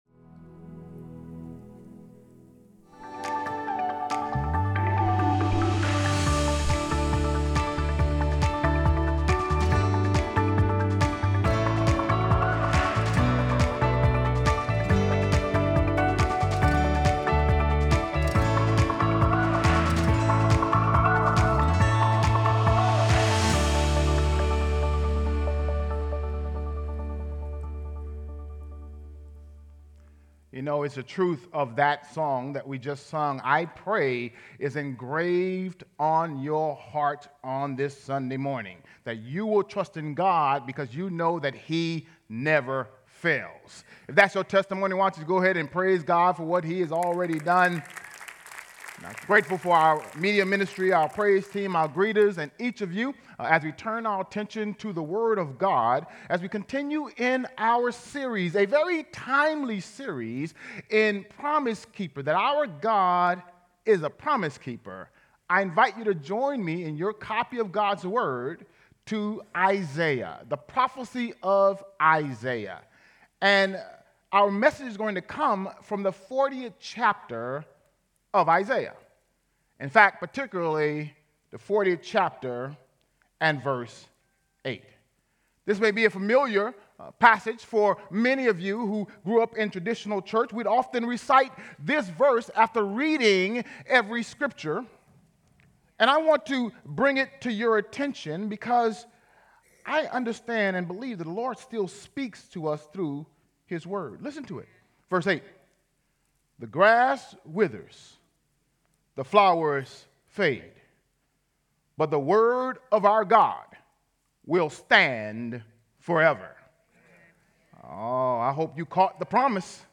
JV-SERMON-ENG-AUDIO-7.14.24.mp3